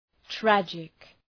Προφορά
{‘trædʒık}